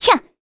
Valance-Vox_Attack3_kr.wav